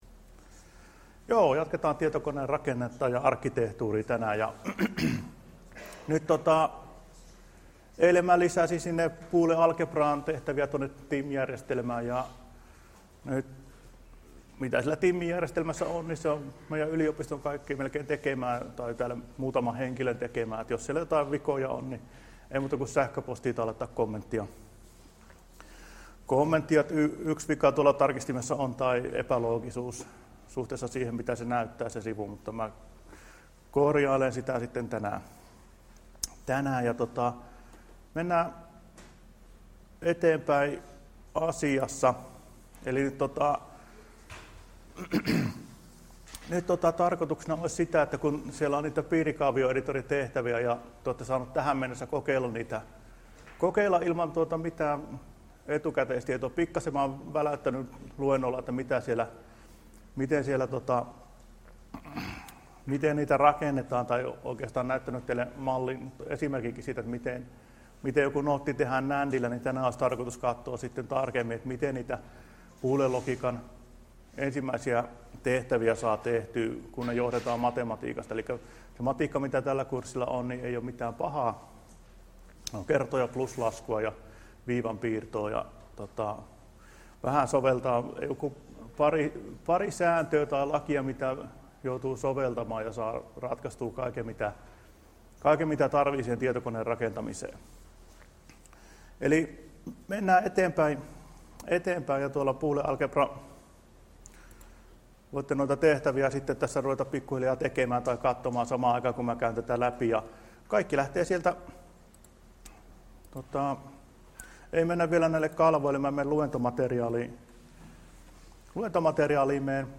Luento 20.9.2016 — Moniviestin